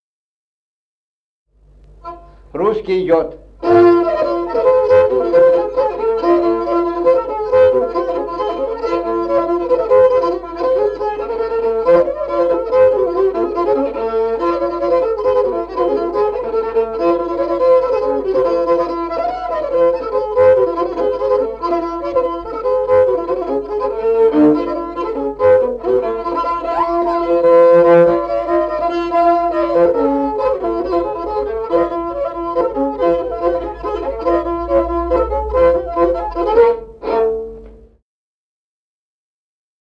Музыкальный фольклор села Мишковка «Русский», репертуар скрипача.